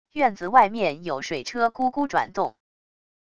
院子外面有水车咕咕转动wav音频